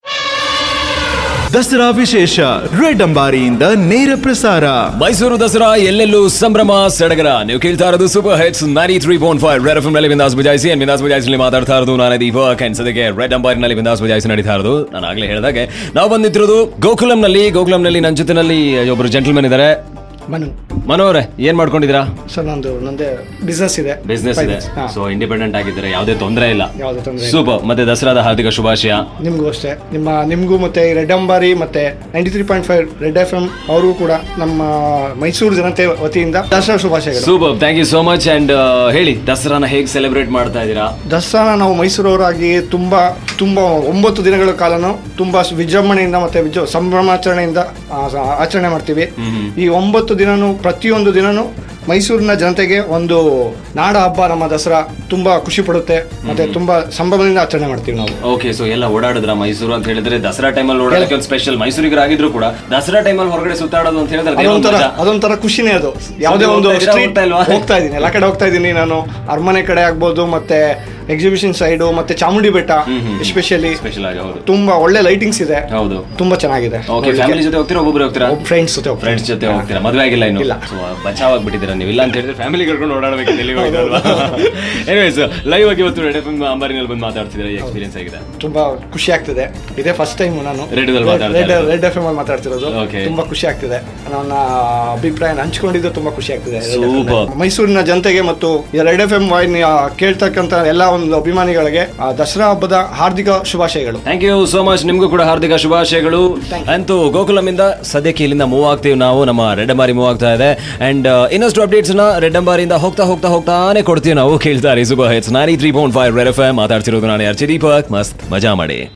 Red Ambari is a Studio on wheel where listeners and celebrities share their festive excitement